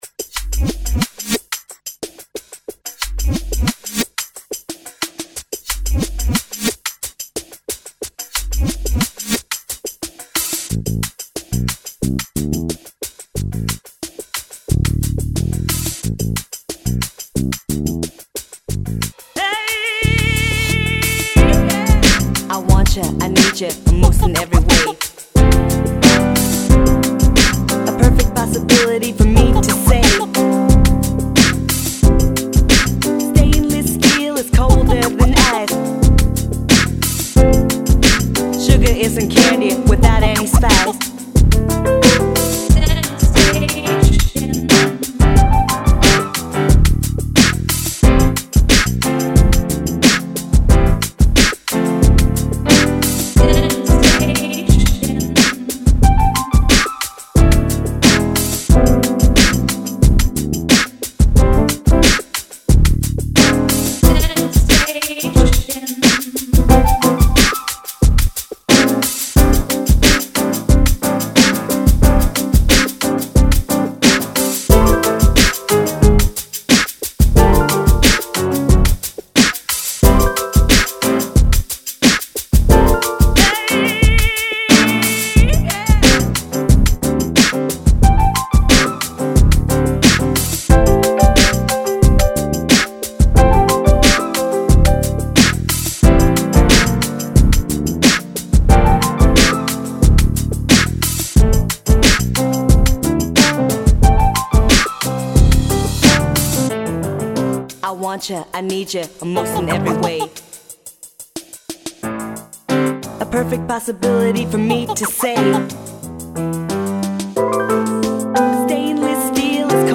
No son muy creativas, la verdad que cuando escucho las canciones me salen muy bakalaeras y duran mucho, pero en fin, las hice en plan hobbie y no pretendía tampoco mucho con ellas.
SENSATION (Hecha con el Hip Hop Ejay)